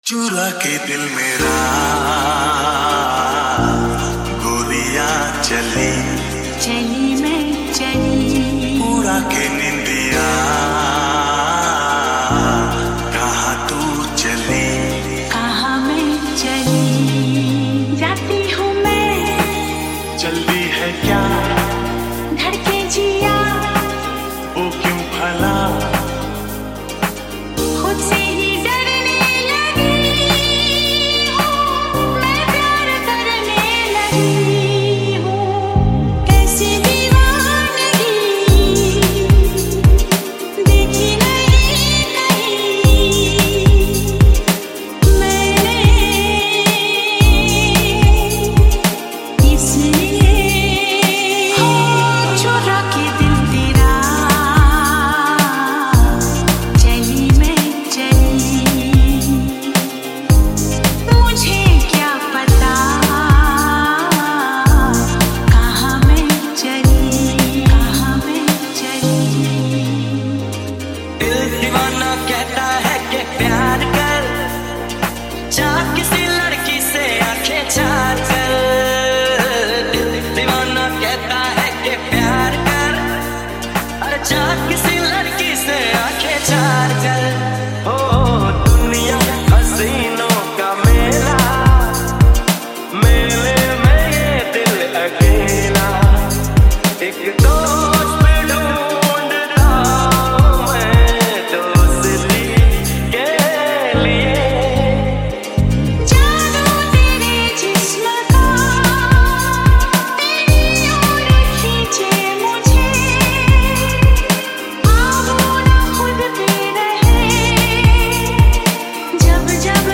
MASHUP 2025